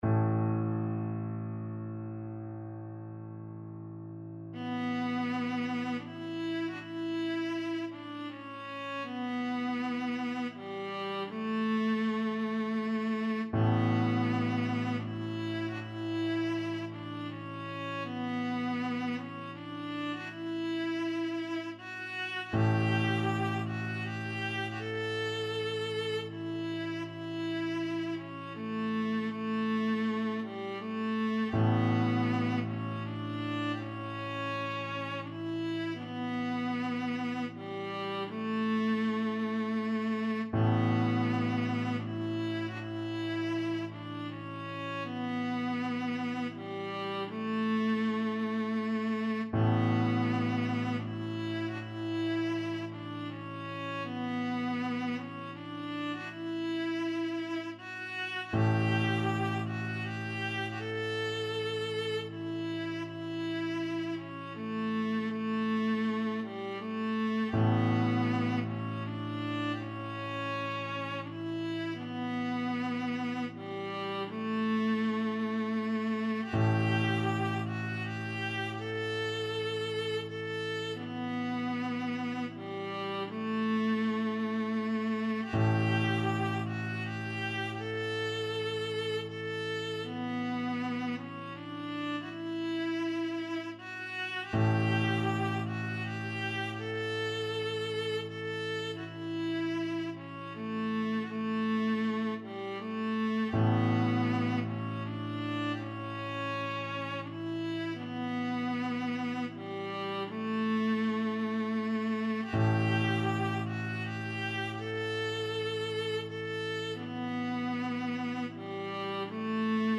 Viola
A beautiful lament, as played by a lone piper at the end of the funeral of Queen Elizabeth II at Westminster Abbey on 19th September 2022.
3/4 (View more 3/4 Music)
D major (Sounding Pitch) (View more D major Music for Viola )
Slowly and freely, in the manner of bagpipes =80
Traditional (View more Traditional Viola Music)